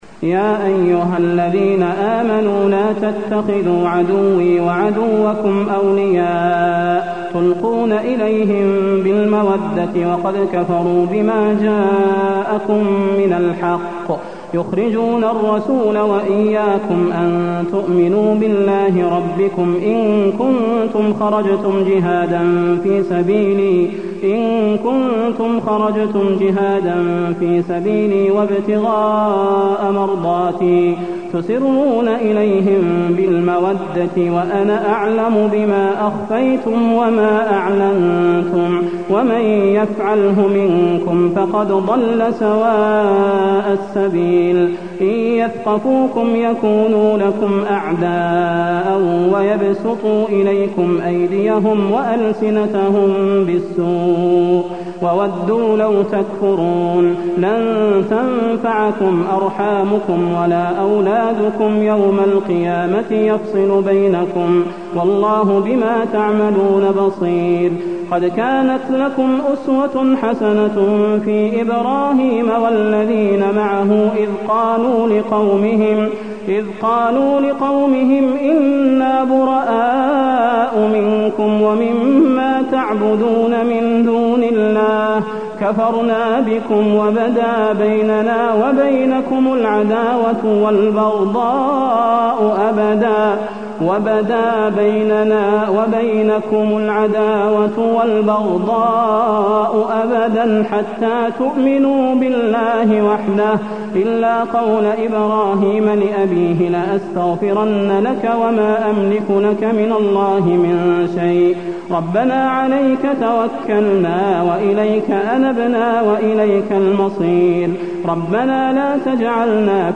المكان: المسجد النبوي الممتحنة